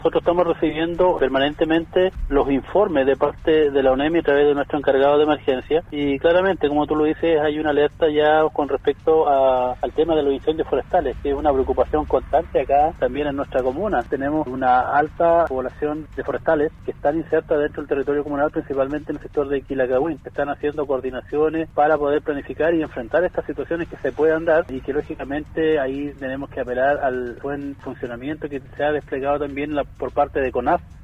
En conversación con Radio Sago, los alcaldes de ambas comunas se refirieron a la situación de altas temperaturas pronosticado para estos días en la región y con ello la posible ocurrencia de incendios forestales.